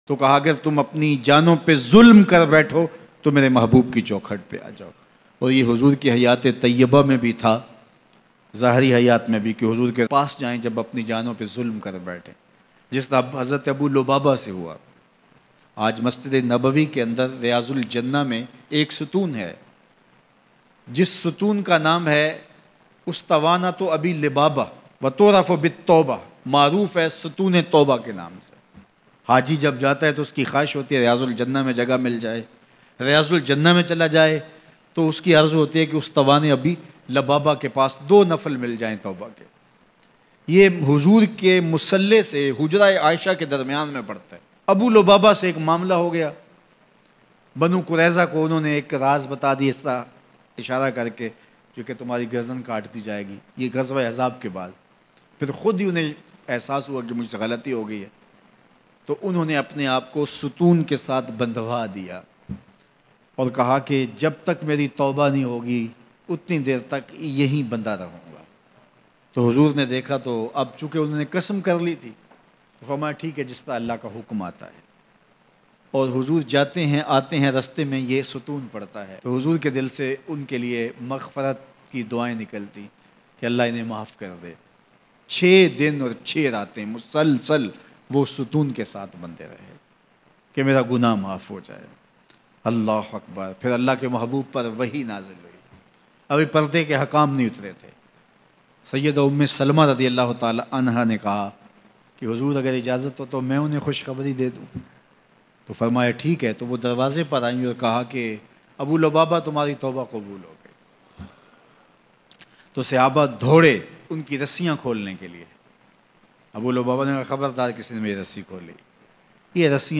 Mehboob ki Dehleez se hote hove aao Bayan